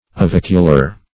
\O*vic"u*lar\
ovicular.mp3